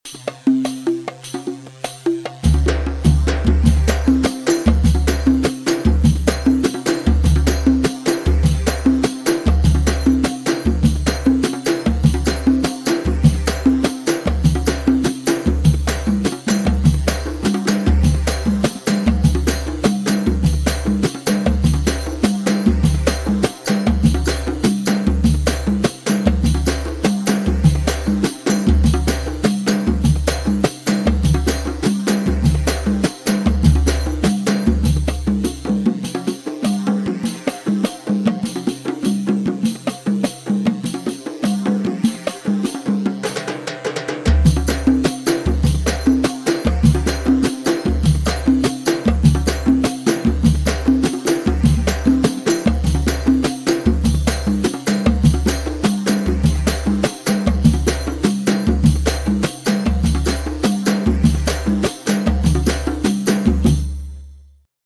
Bandari 6/8 150 بندري
Bandari-6-8-150-mix.mp3